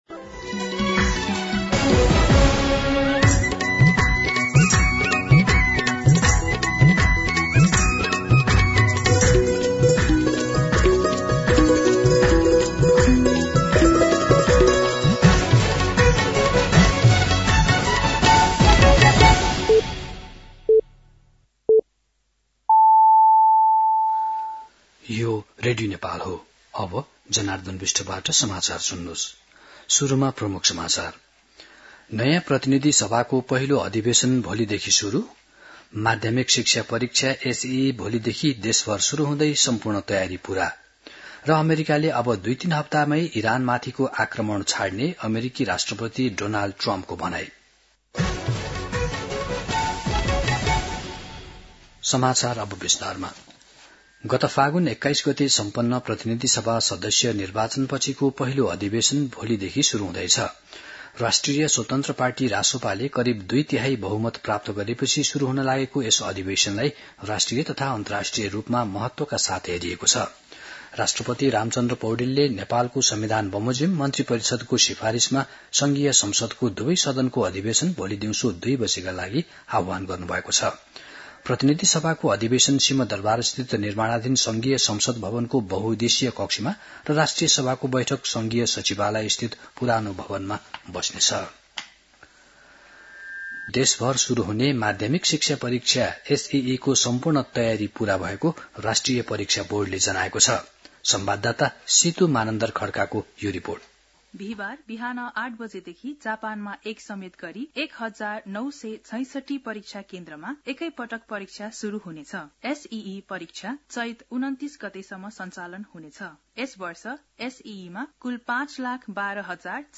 दिउँसो ३ बजेको नेपाली समाचार : १८ चैत , २०८२
3-pm-News-18.mp3